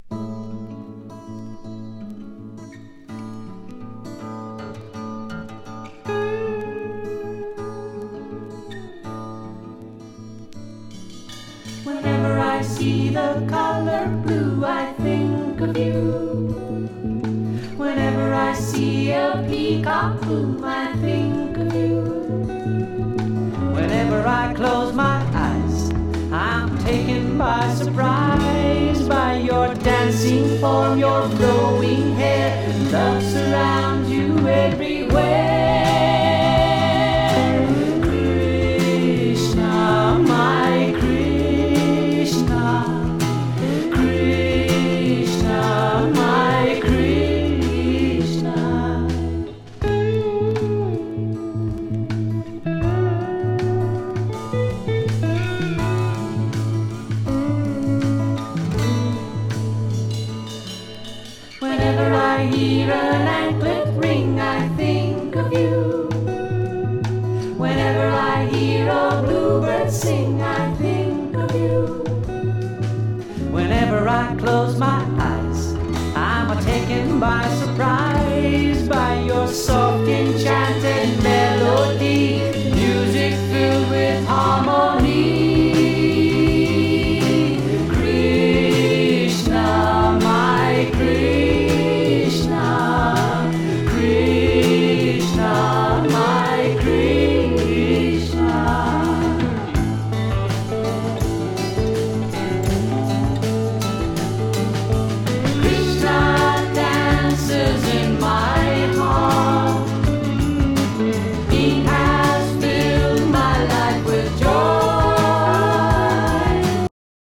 イギリス出身のSSWによる1stと思われます。